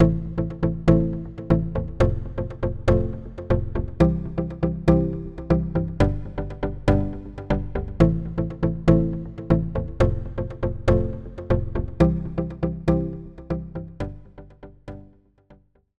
IRCAM Prepared Piano 2
This collection offers 45 preparations made on a C7 Grand Piano:
Workstation-IRCAM-Preparedpiano-2.mp3